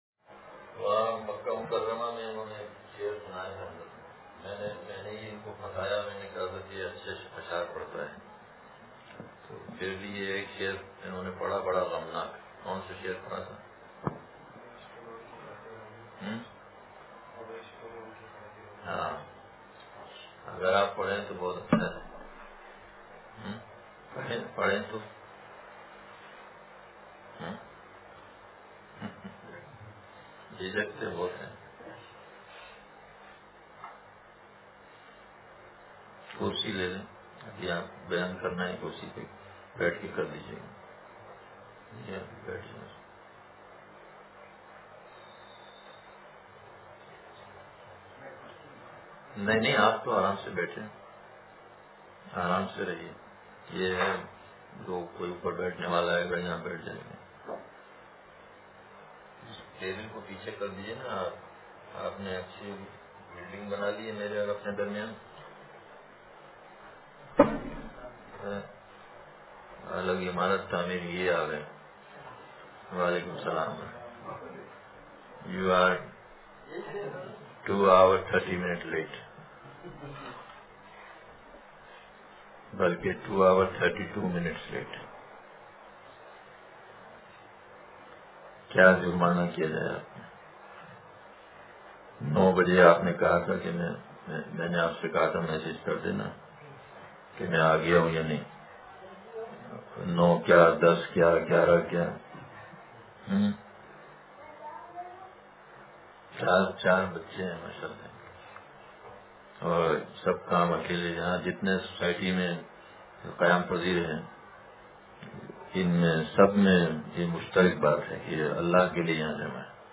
زبانِ عشق – مجلس بروز اتوار